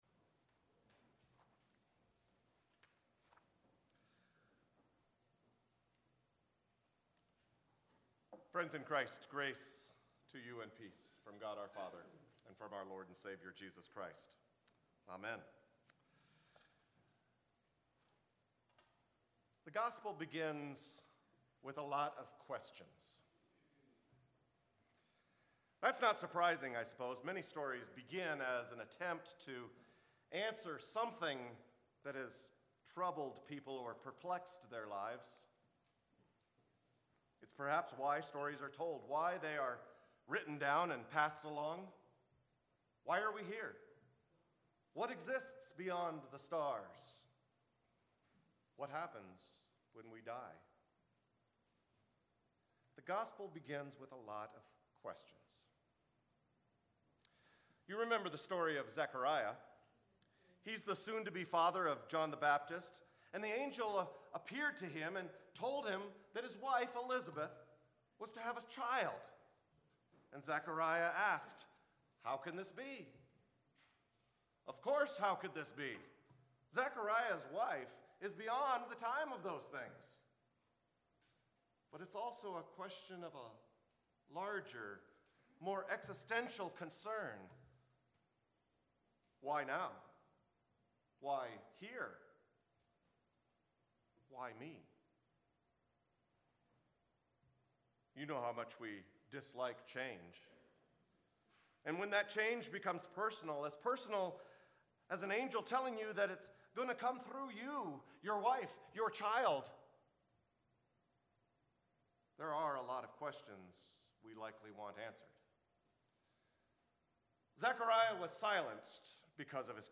Sermons | Bethlehem Lutheran Church